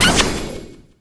colette_atk_hit_01.ogg